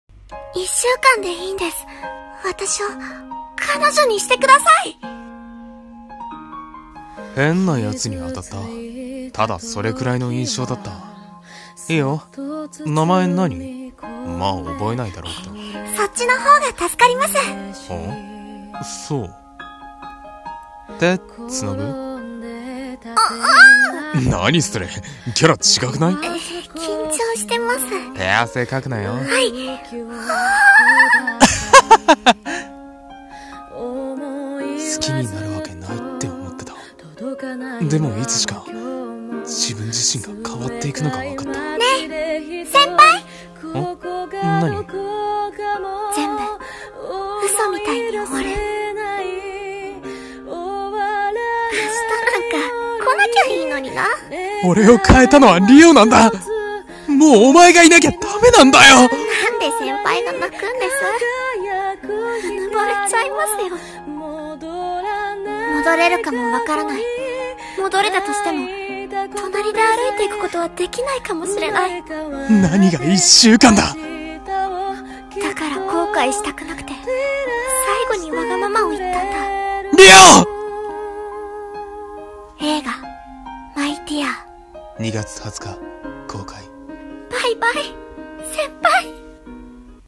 【予告風声劇】